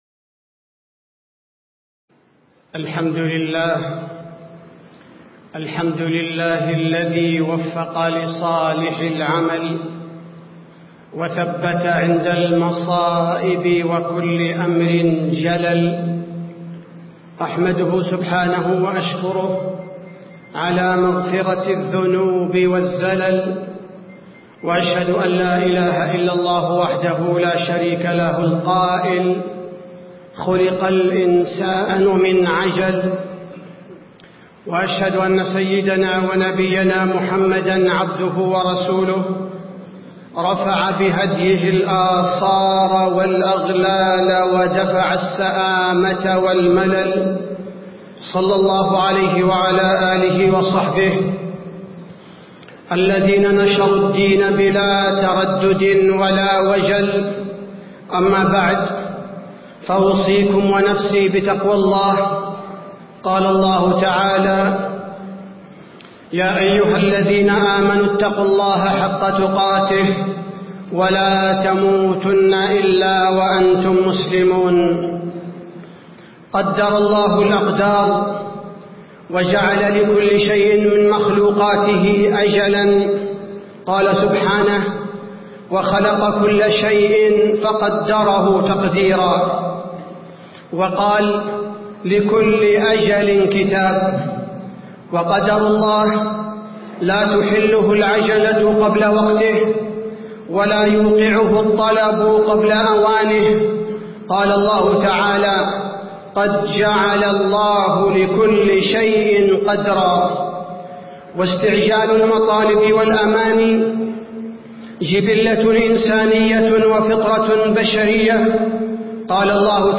تاريخ النشر ١٦ جمادى الآخرة ١٤٣٧ هـ المكان: المسجد النبوي الشيخ: فضيلة الشيخ عبدالباري الثبيتي فضيلة الشيخ عبدالباري الثبيتي التحذير من العجلة